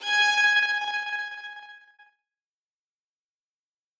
Geige und anteilig im Send Reverb + irgendwas mit Stuttereffekt o.ä - danach noch Transientshaping, Distorsion und Kompression. Und schon hast Du einen bremsenden Zug